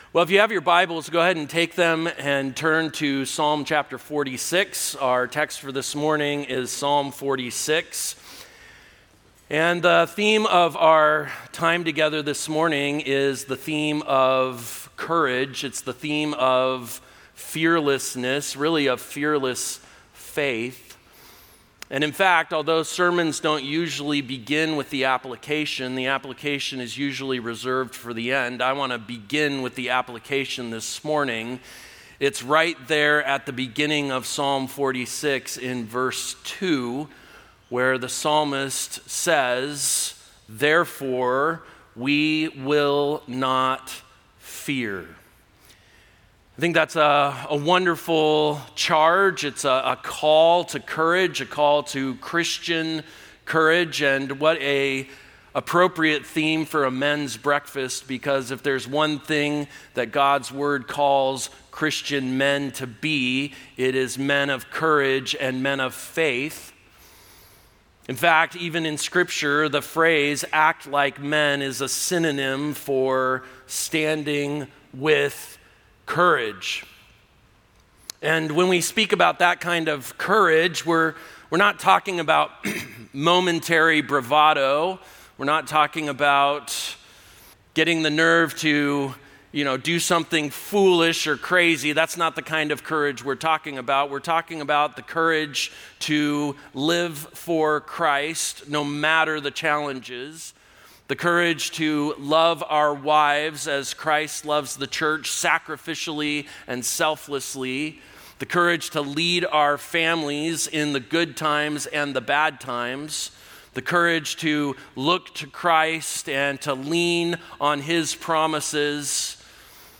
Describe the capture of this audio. Men Men's Breakfasts Audio ◀ Prev Series List Next ▶ Previous 30.